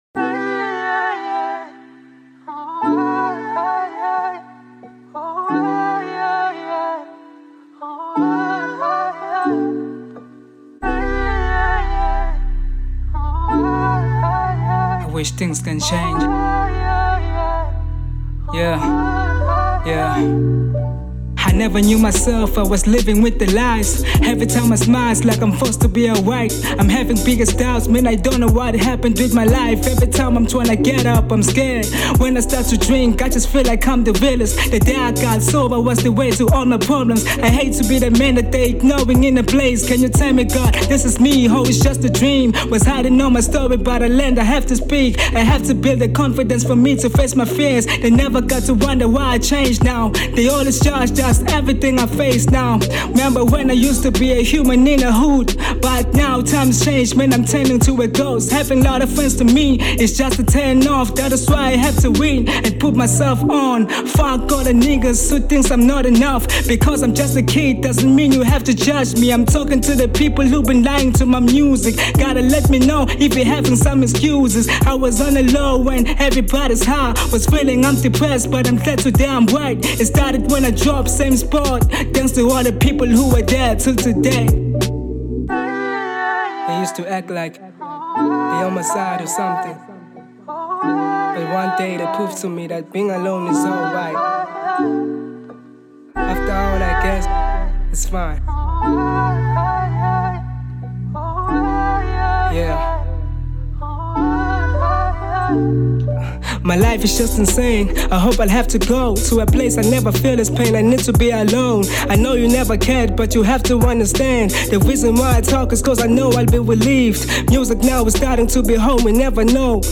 03:12 Genre : Hip Hop Size